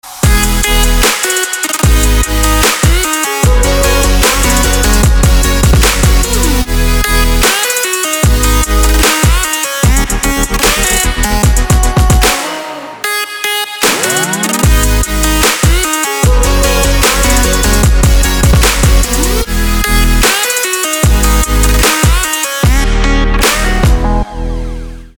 громкие
dance
Electronic
электронная музыка
без слов
club